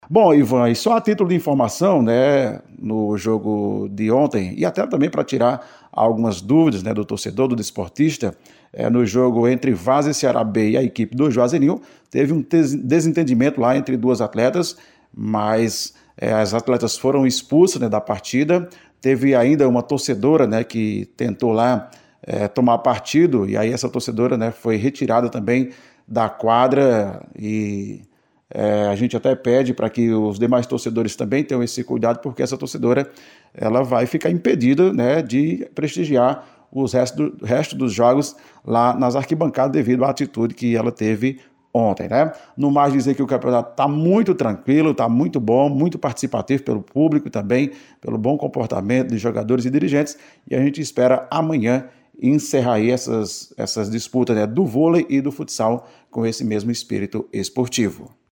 Em áudio enviado para a Rádio Cultura, o secretário de esportes Cícero Sousa disse que medidas foram tomadas. Ele explicou o motivo que gerou a confusão.